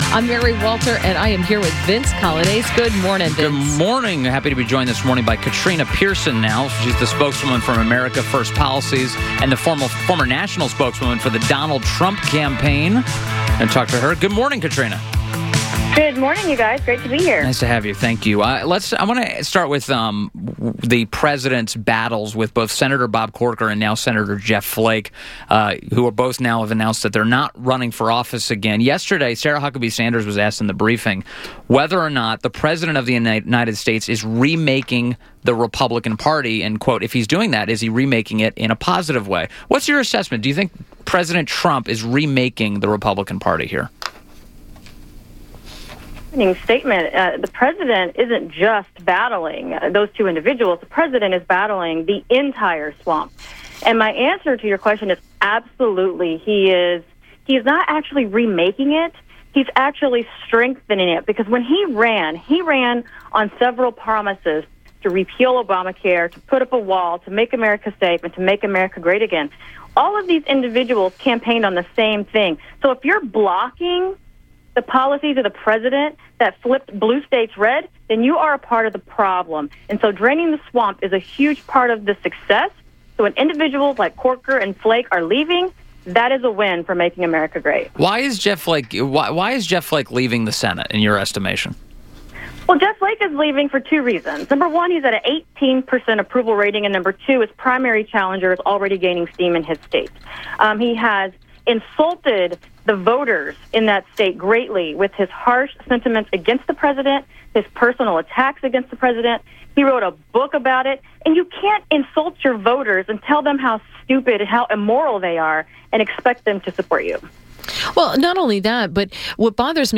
WMAL Interview - KATRINA PIERSON - 10.25.17
7:35 - INTERVIEW - KATRINA PIERSON - Spokesperson, America First Policies; Former National Spokesperson, Donald Trump Campaign